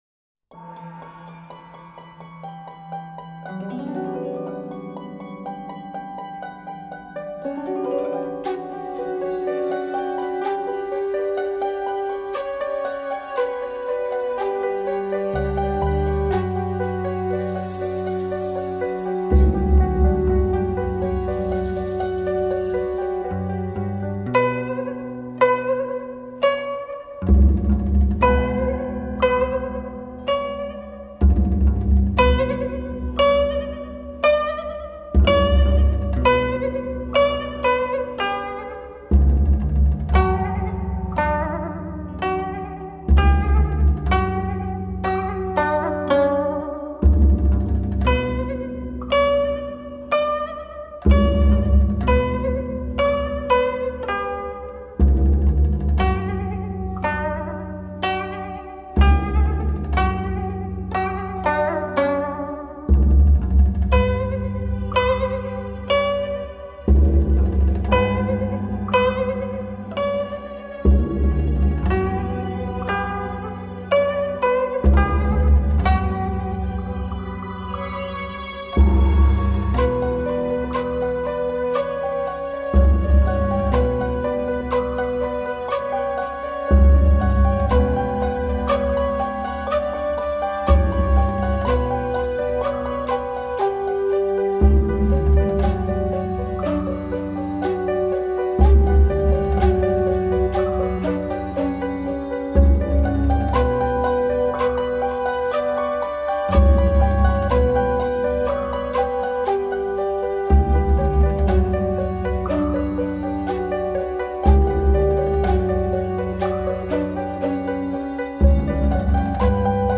由始至终的电声乐中穿插古老的音乐元素,